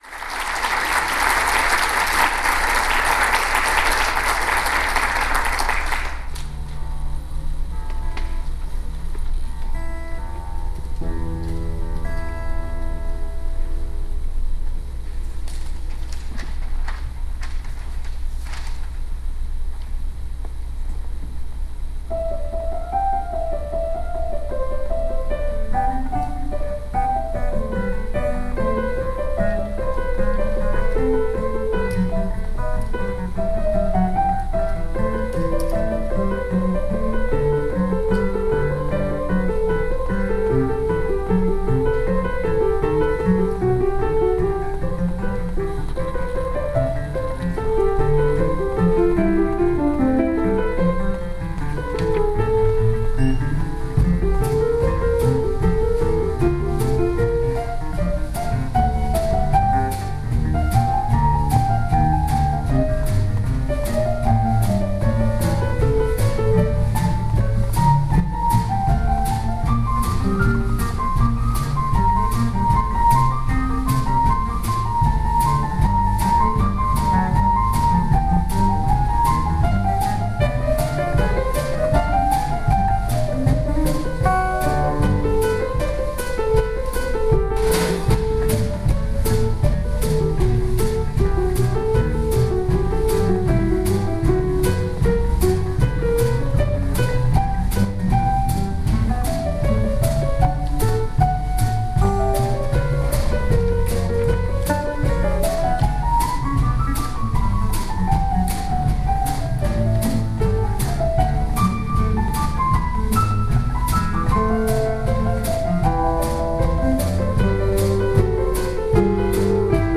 We were all amateurs, no other aim than to have fun playing together.
The tracks are not so bad considering it was a simple recorder in the hall...
Concert for classical guitar and jazz piano trio